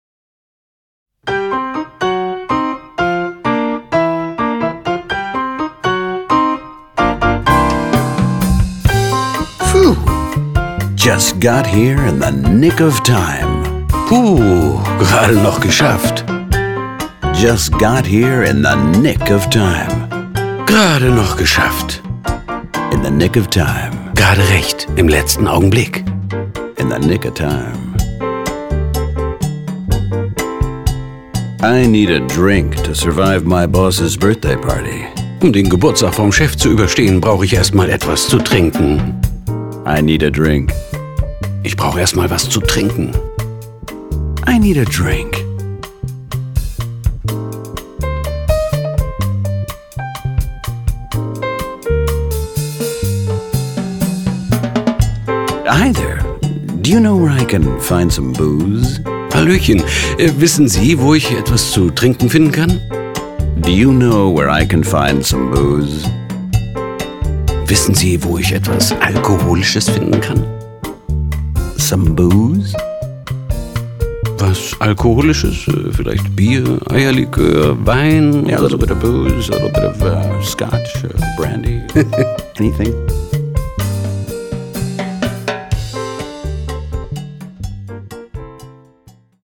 Best of.Coole Pop & Jazz Grooves / Audio-CD mit Booklet